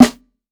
TC SNARE 06.wav